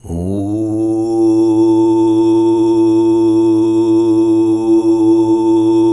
TUV2 DRONE04.wav